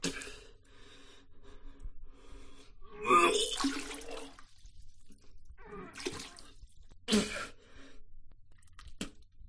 Descarga de Sonidos mp3 Gratis: vomito 3.
vomit-puke.mp3